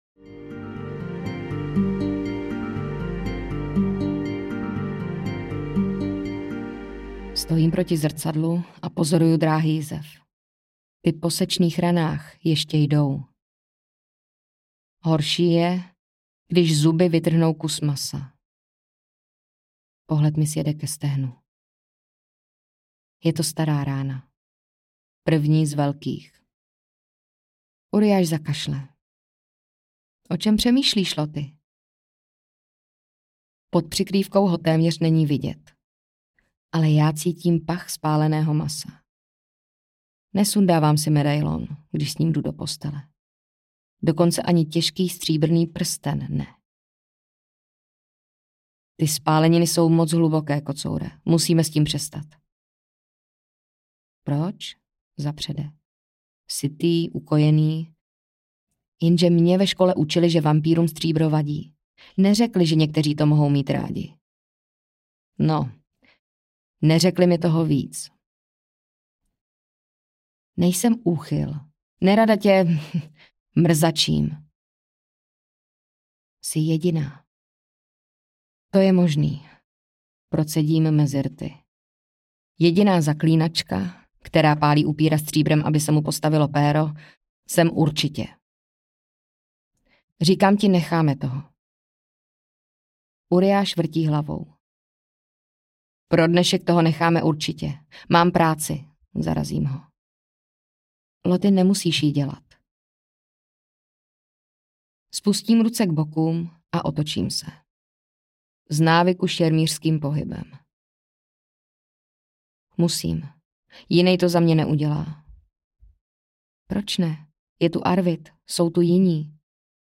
Zmetek z temnot audiokniha
Ukázka z knihy
zmetek-z-temnot-audiokniha